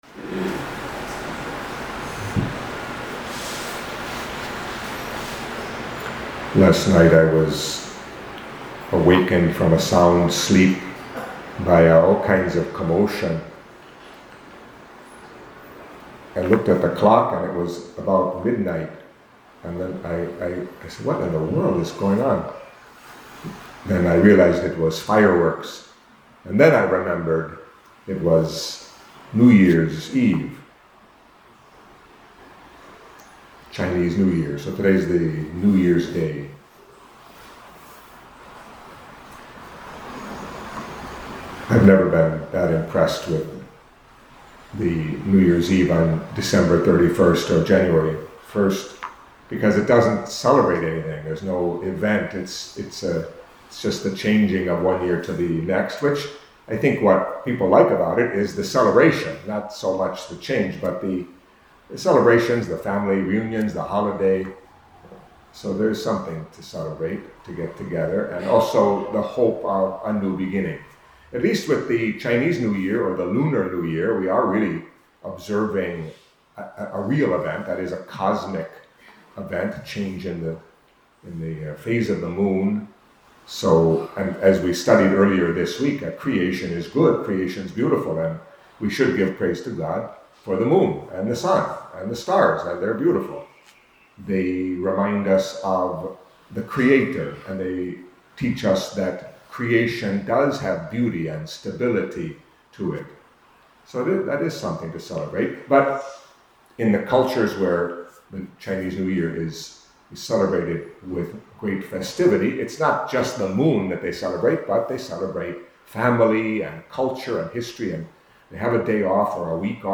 Catholic Mass homily for Friday of the Fifth Week in Ordinary Time